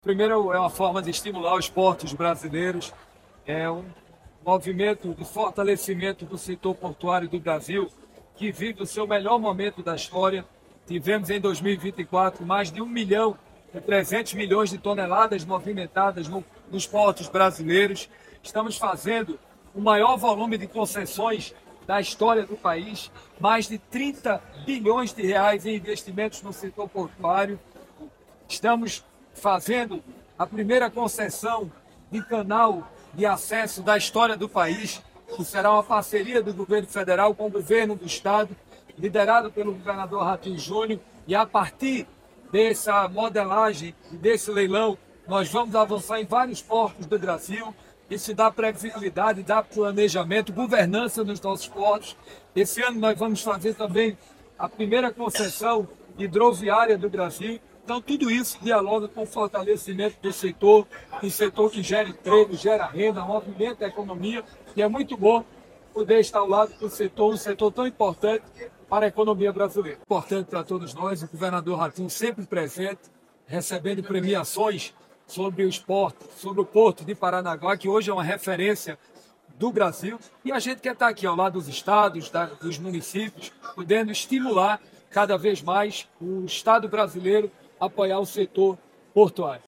Sonora do ministro de Portos e Aeroportos, Silvio Costa Filho, sobre a Portos do Paraná ser hexacampeã do principal prêmio de gestão portuária do Brasil